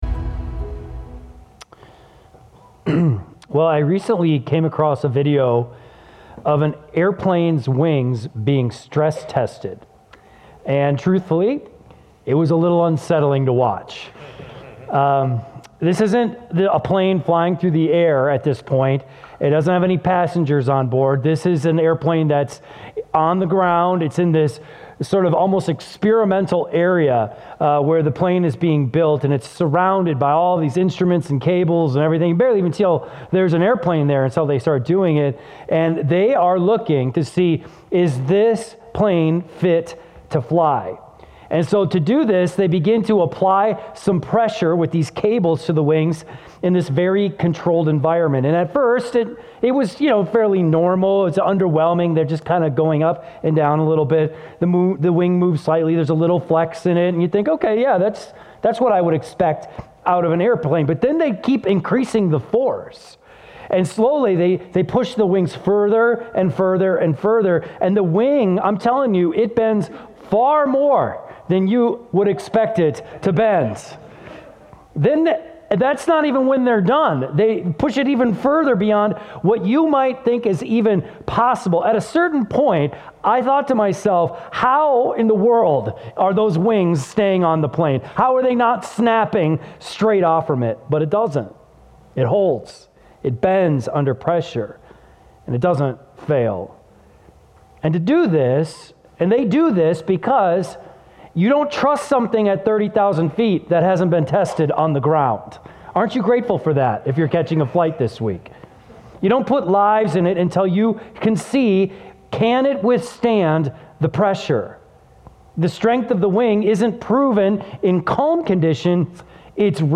keyboard_arrow_left Sermons / James Series Download MP3